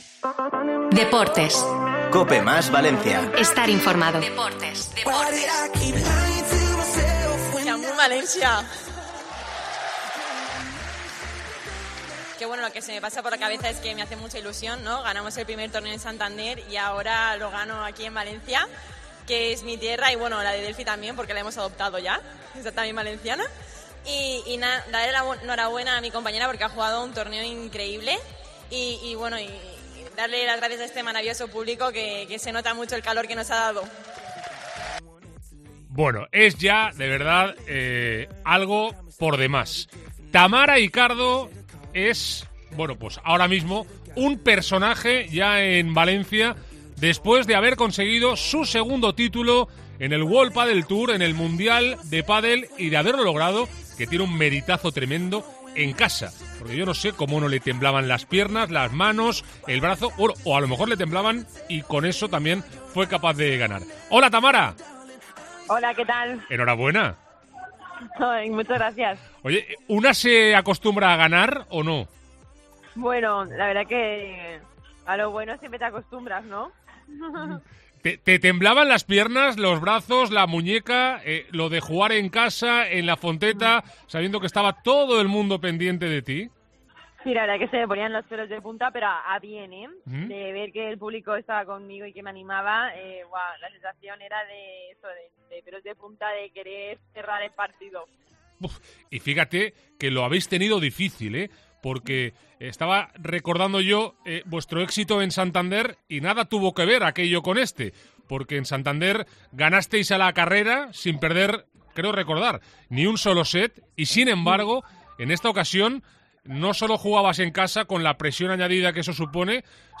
ENTREVISTA COPE
AUDIO. Entrevista a Tamara Icardo en COPE